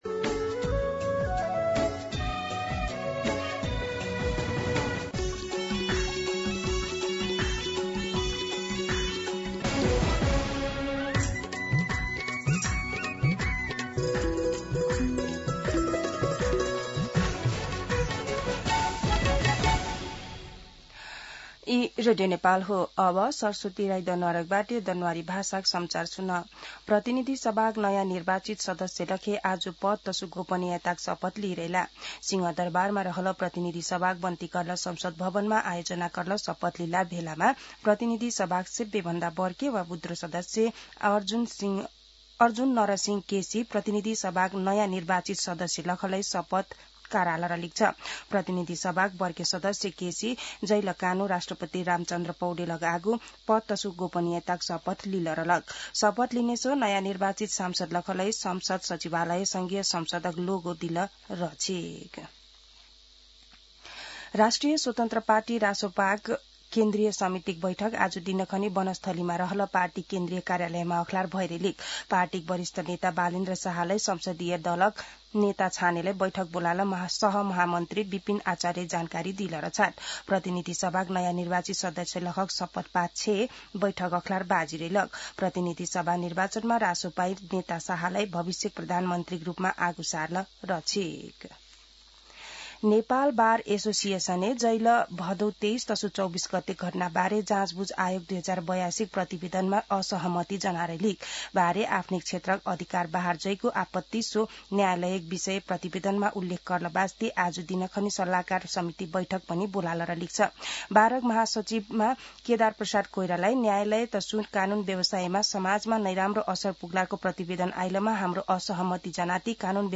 दनुवार भाषामा समाचार : १२ चैत , २०८२